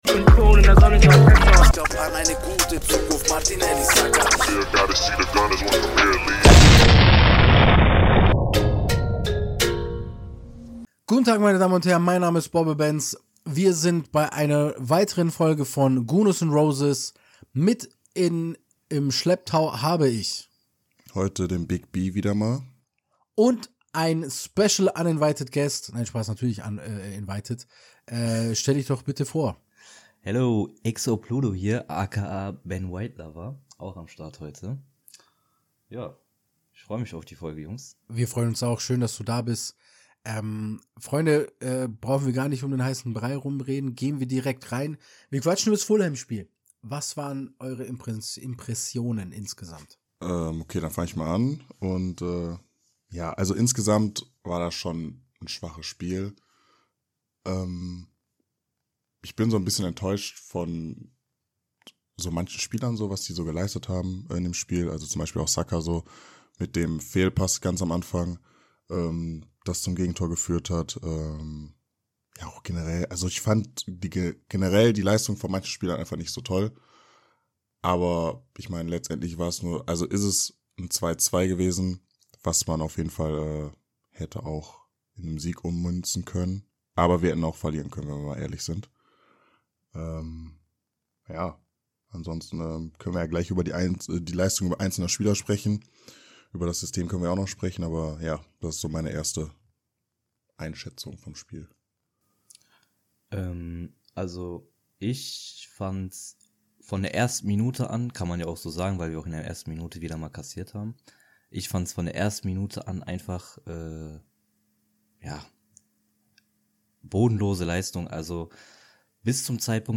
Wir quatschen heute zu dritt über das Fulham Spiel und was wir für eine CL-Gruppe gerne hätten.